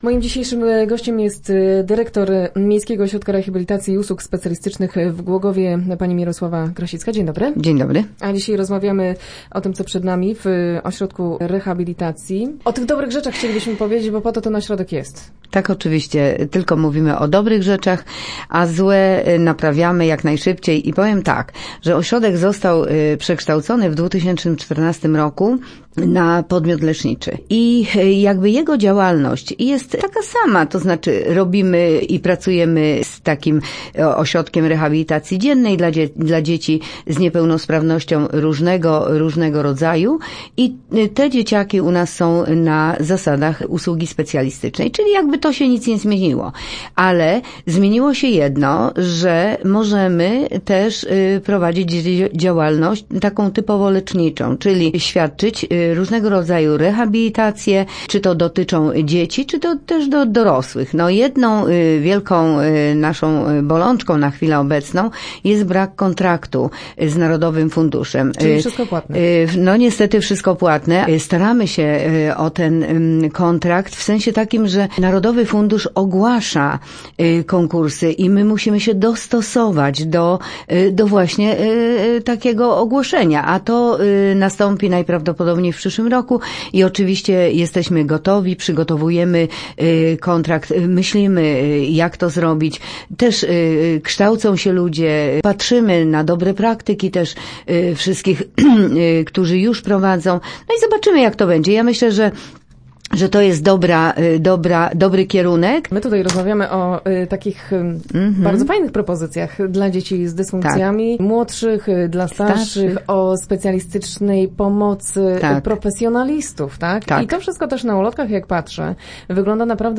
Start arrow Rozmowy Elki arrow MORiUS powalczy o kontrakt